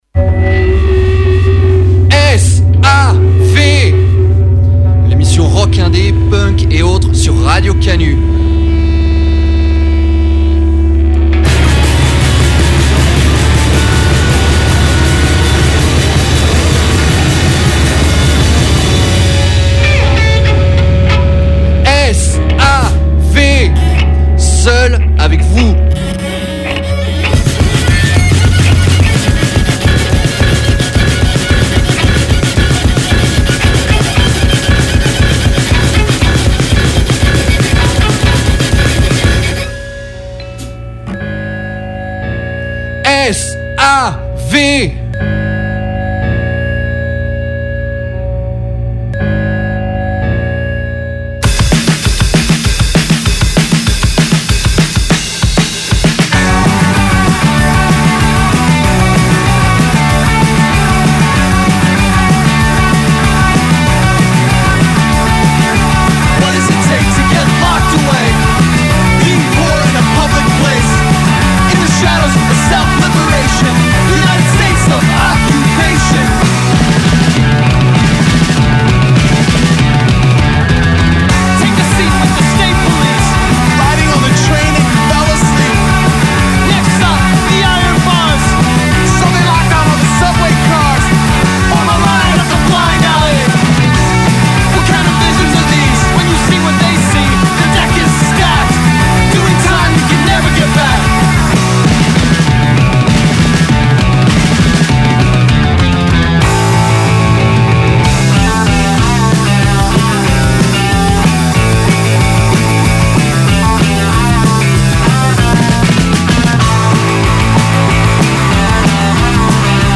Petite sélection des disques indie/punk/hardcore et autres préférés de 2024 !